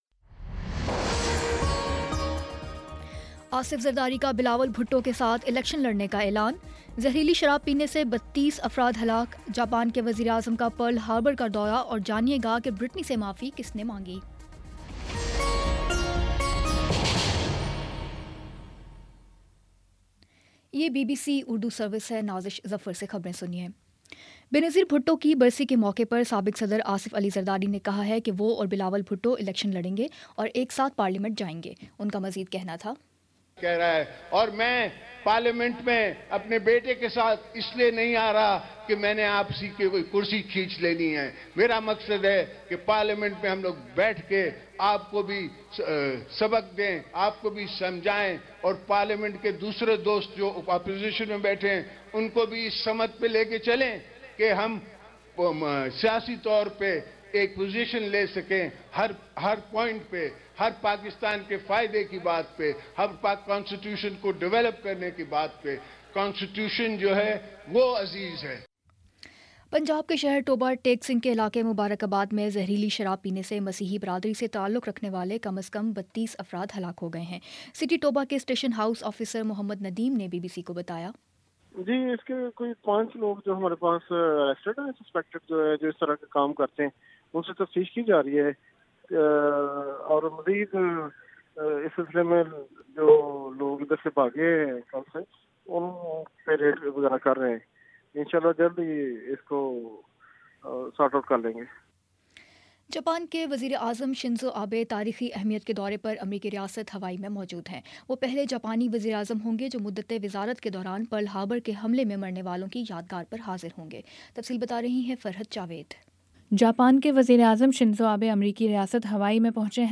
دسمبر 27 : شام پانچ بجے کا نیوز بُلیٹن